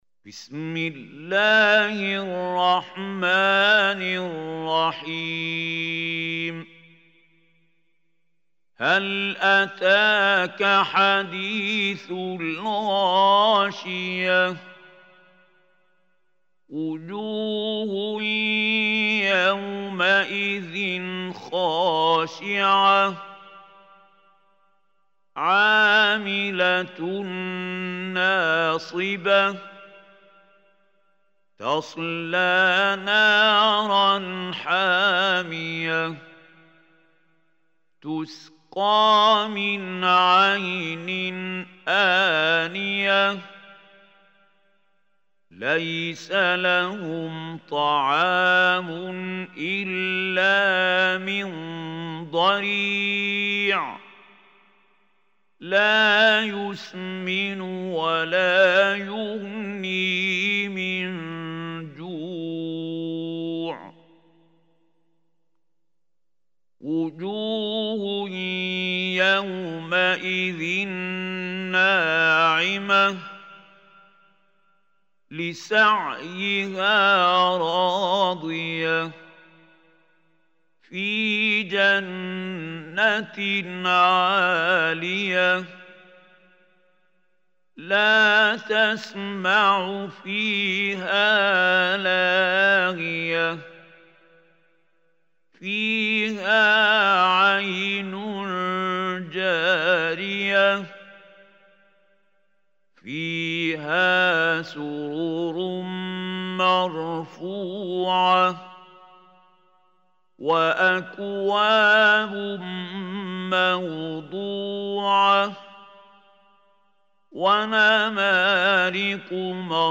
Surah Ghasiyah Recitationby Mahmoud Hussary
Surah Ghasiya is 88 surah of Holy Quran. Listen or play online mp3 tilawat / recitation in the beautiful voice of Sheikh Mahmoud Khalil Hussary.